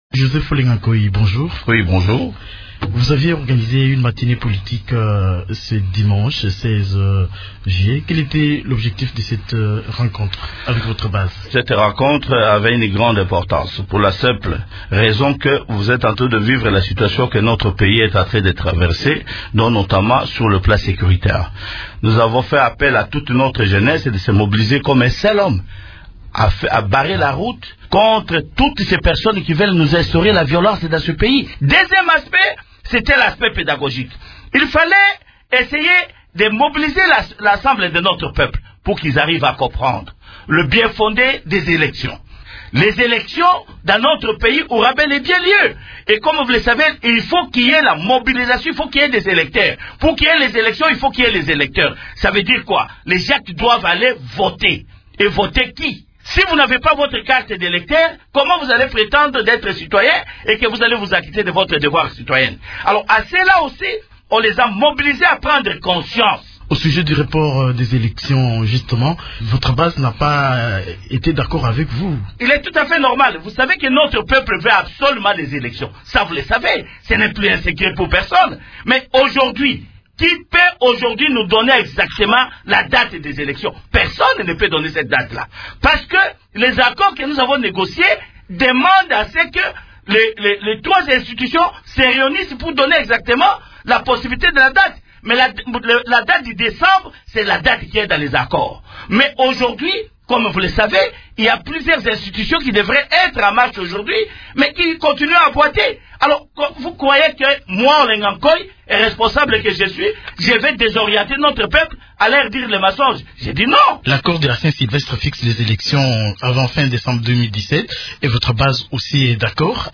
Joseph Olenghankoy est l’invité de la rédaction ce matin.